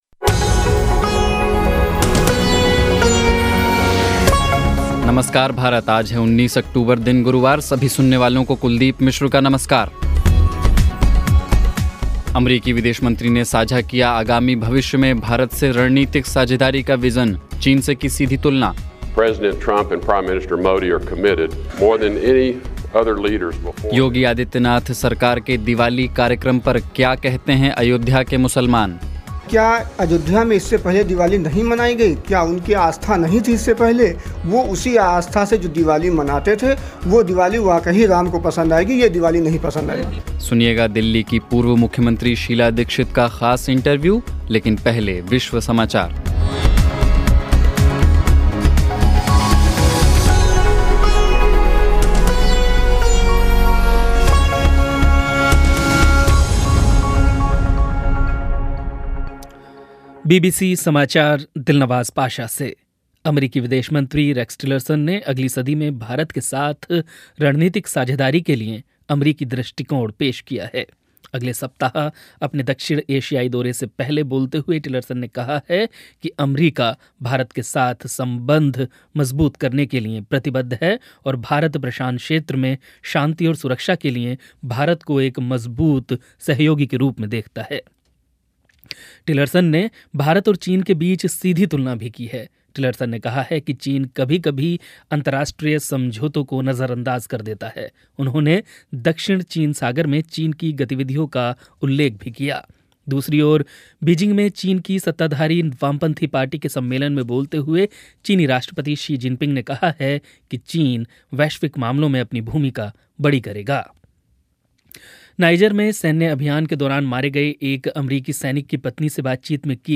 सुनिएगा दिल्ली की पूर्व मुख्यमंत्री शीला दीक्षित का ख़ास इंटरव्यू और अख़बारों की समीक्षा भी